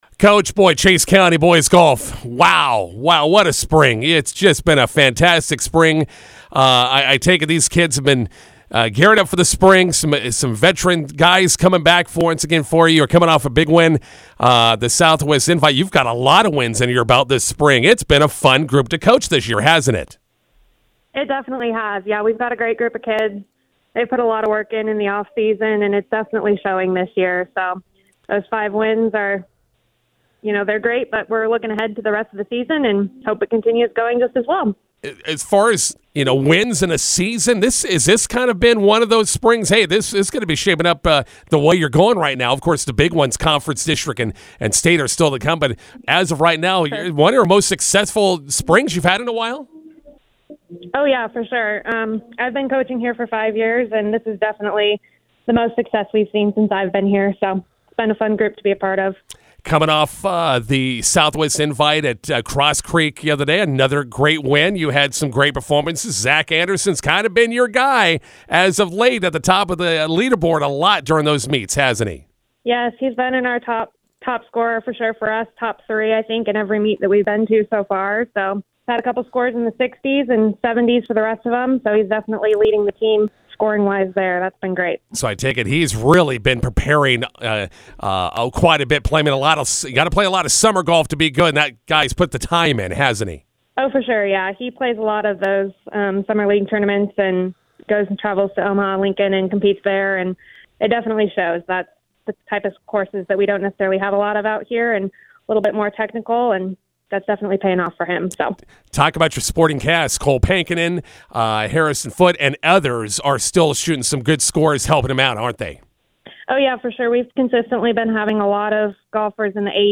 INTERVIEW: Chase County golfers pick up their fifth win of the spring Thursday at Southwest Invite in Cambridge.